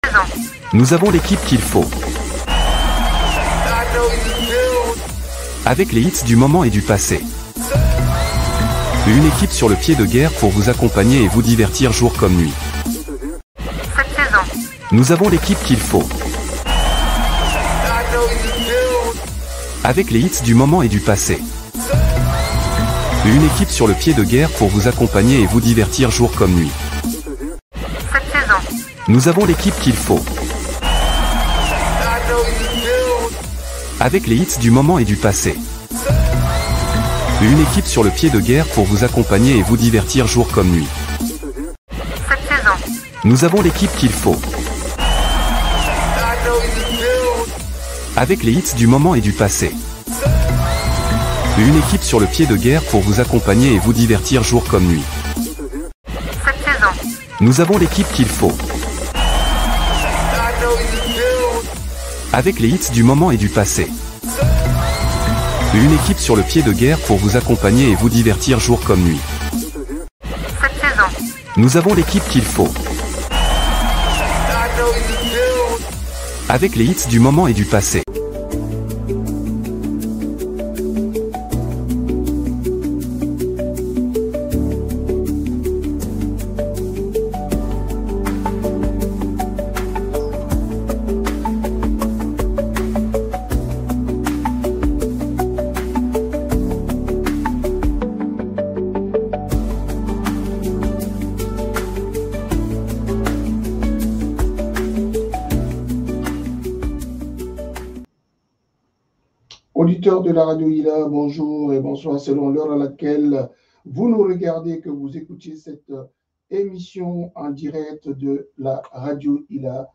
Deuxième émission spéciale Municipales 2026 sur Radio Ylla Dans le cadre de notre série consacrée aux élections municipales 2026, la deuxième émission spéciale s’est tenue sur les ondes de Radio Ylla – La Voix Afro de Strasbourg.